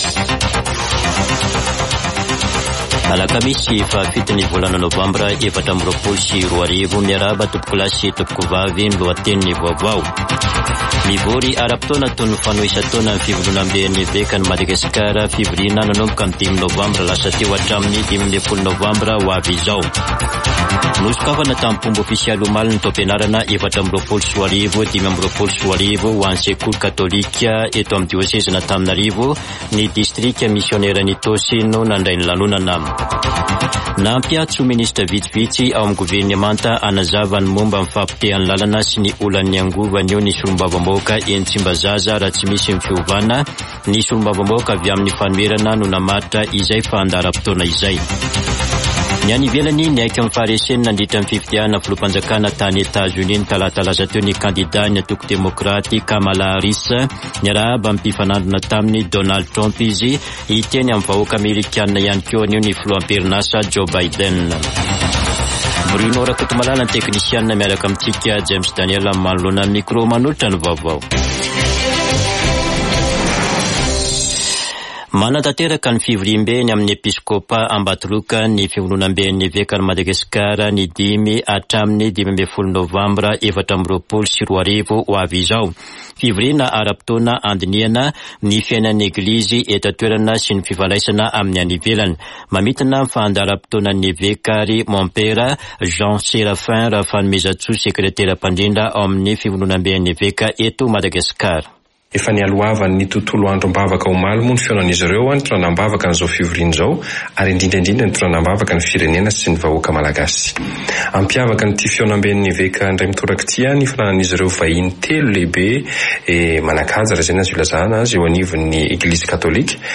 [Vaovao maraina] Alakamisy 7 novambra 2024